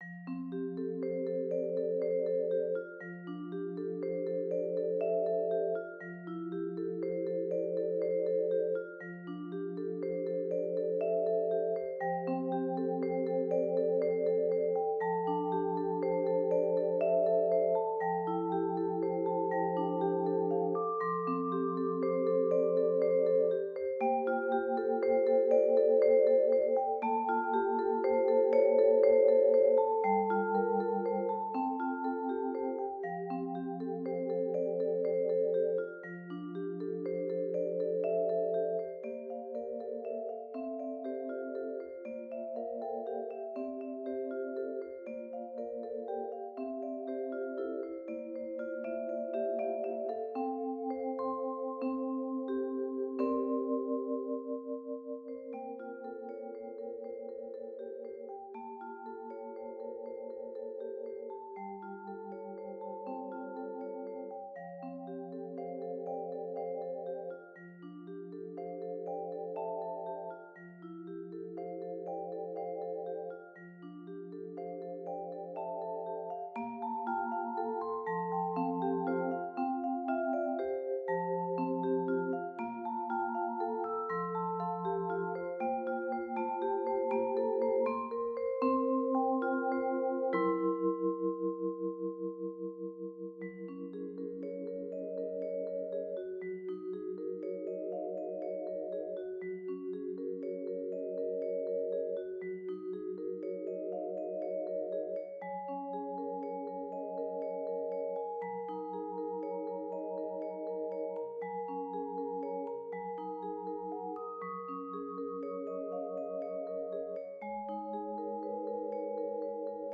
Mallet Solo Level